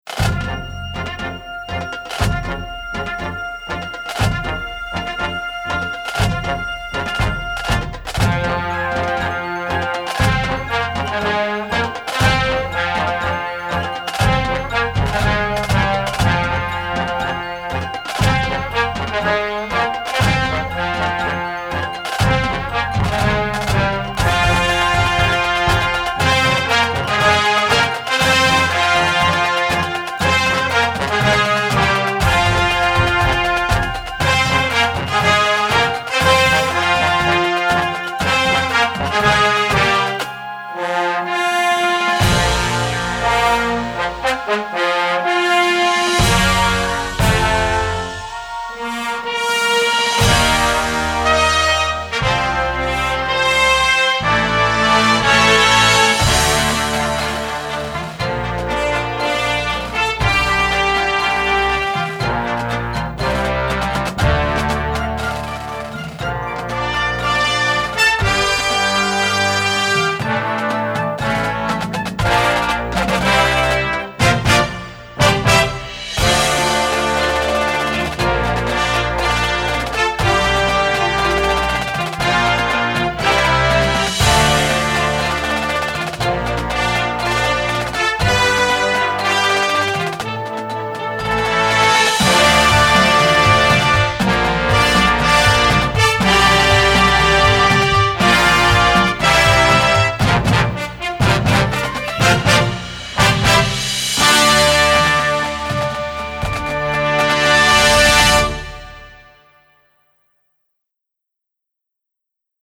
Gattung: Filmmusik
Marching-Band
Besetzung: Blasorchester